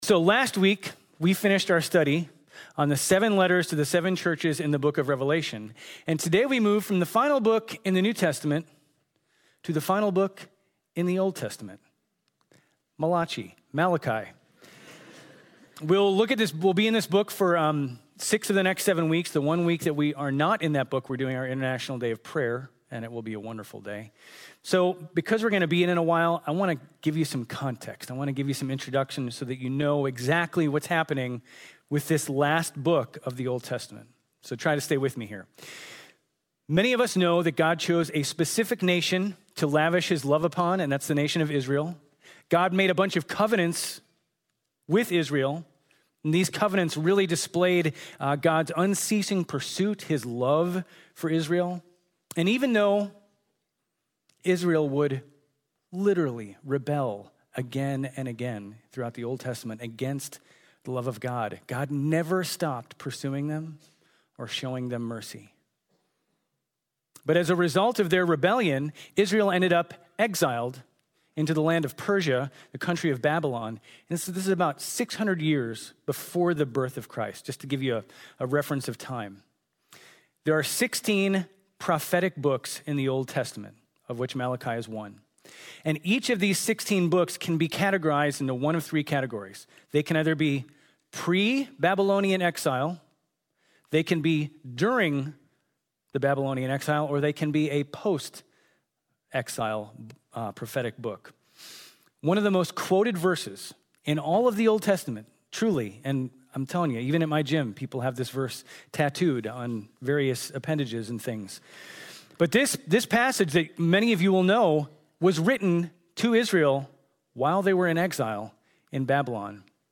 GCC-OJ-October-9-Sermon.mp3